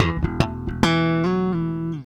Bass Lick 35-06.wav